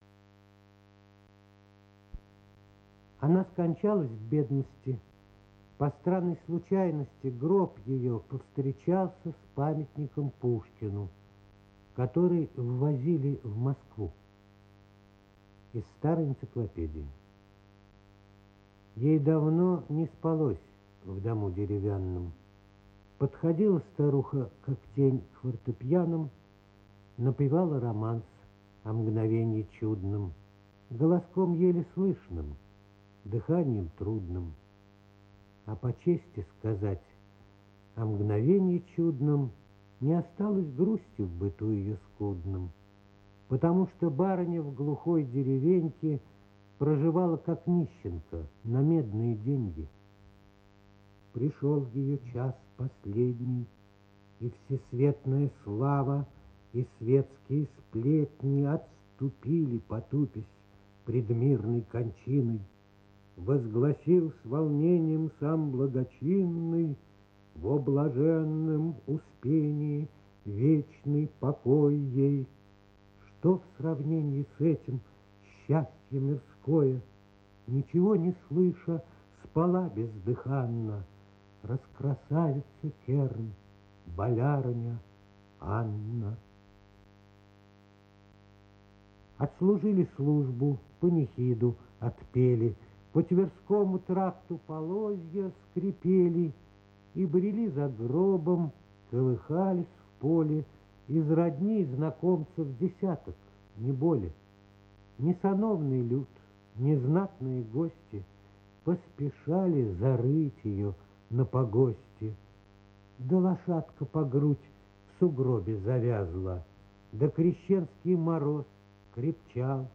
1. «Павел Антокольский – Баллада о чудном мгновении (чит. Ю.Катин-Ярцев)» /